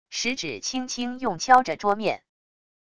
食指轻轻用敲着桌面wav音频